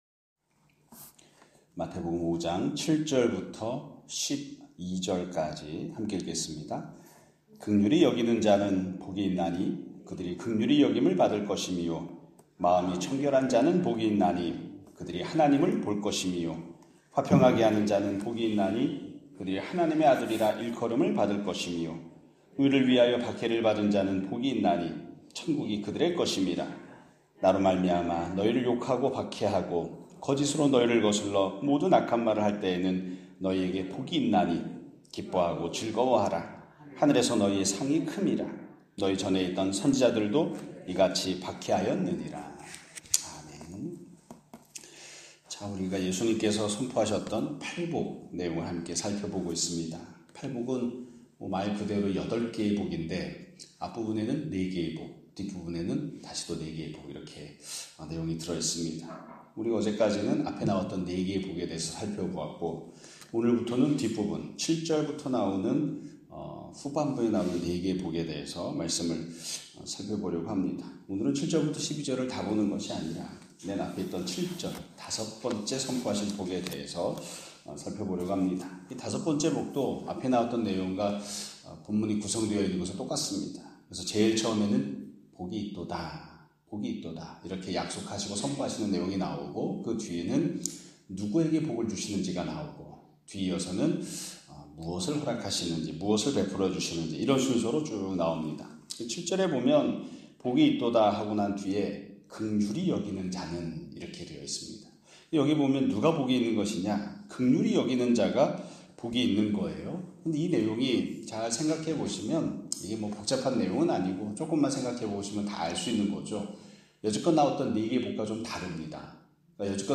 2025년 5월 13일(화요일) <아침예배> 설교입니다.